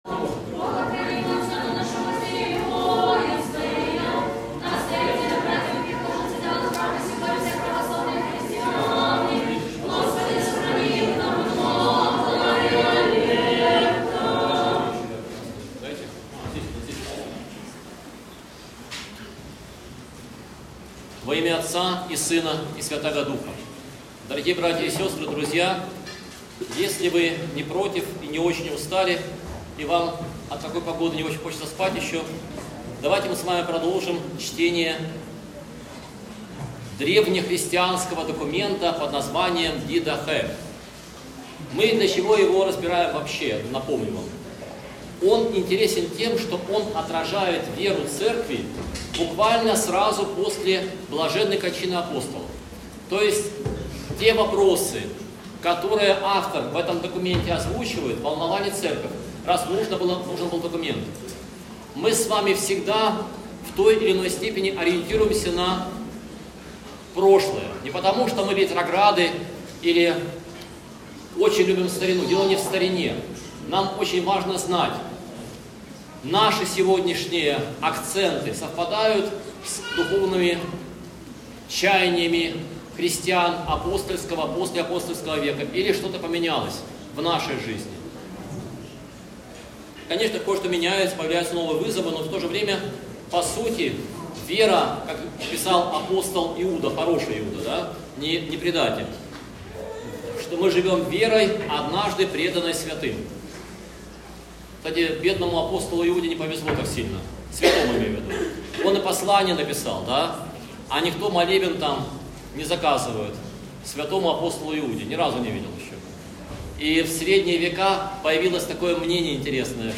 Проповедь с Божественной Литургии 24.09.2023
В неделю 16-ую по Пятидесятнице в приходе храма Рождества Христова в городе Бресте была совершена Божественная литургия с чтением отрывка из Евангелия от Матфея, глава XXV, стихи 14 – 30.